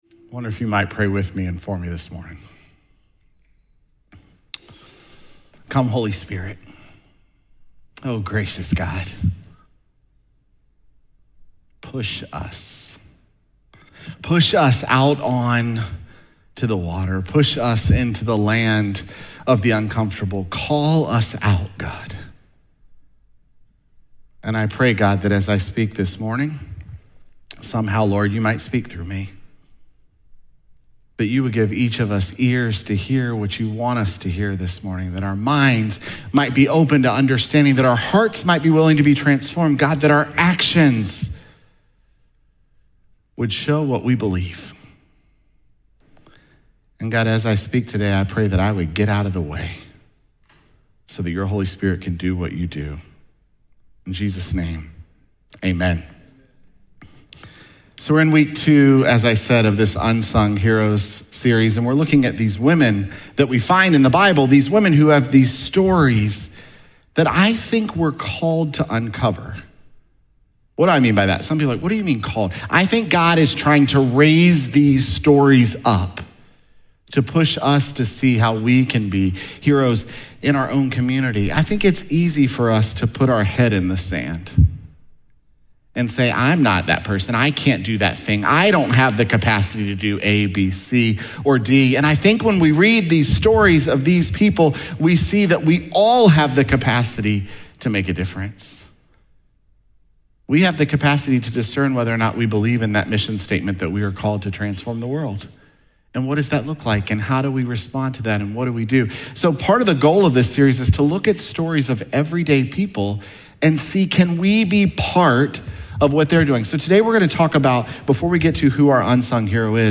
Sermons
Jul27SermonPodcast.mp3